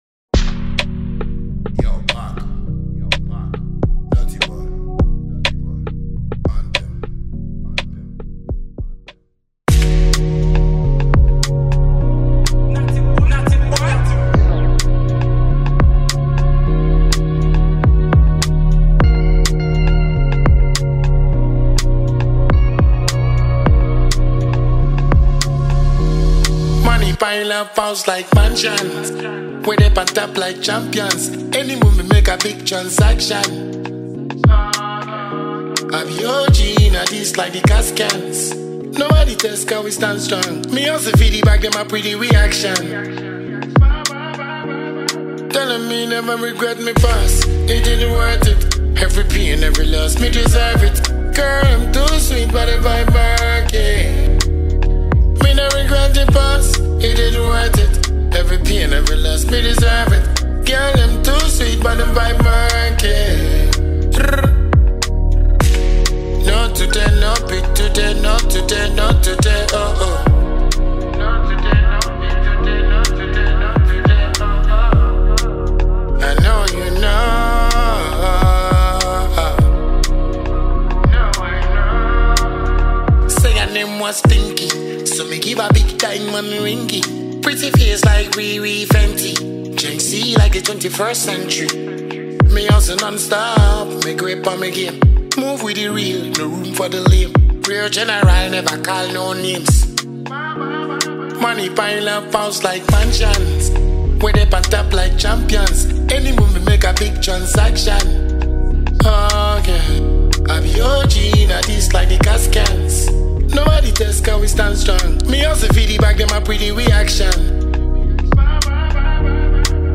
Ghana Music
dnacehall